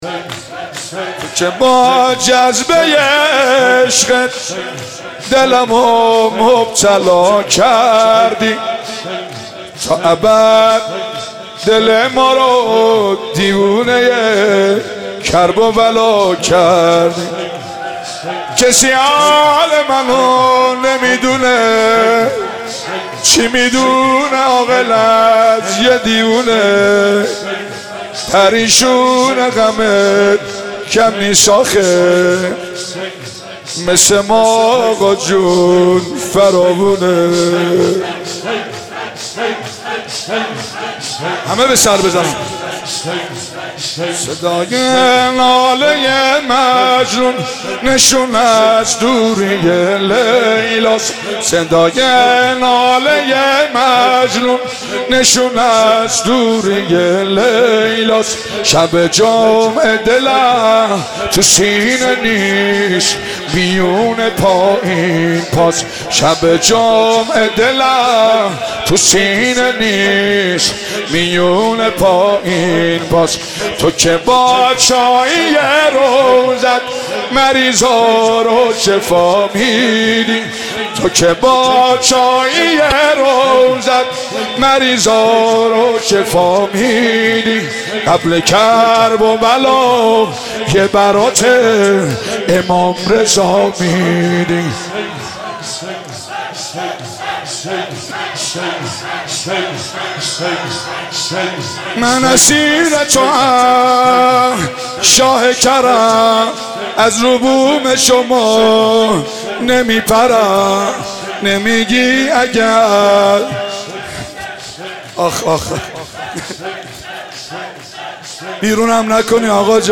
مداحی
شب ششم محرم